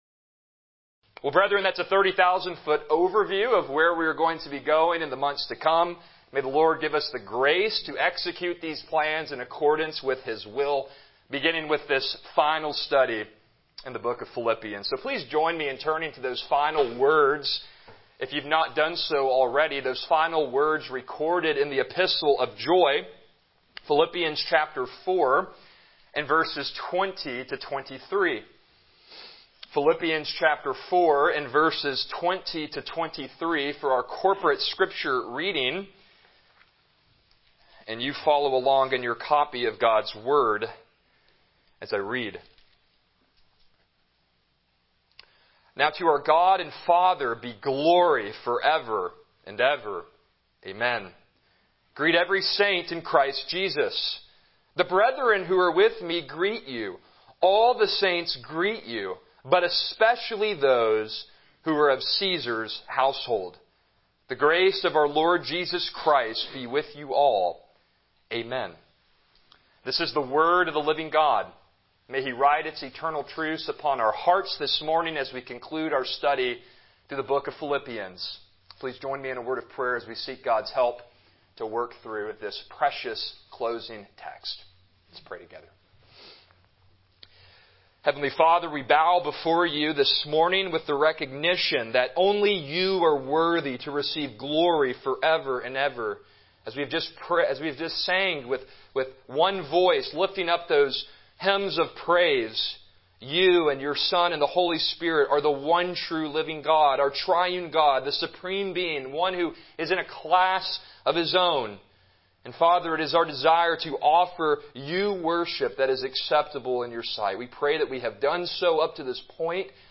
Passage: Philippians 4:20-23 Service Type: Morning Worship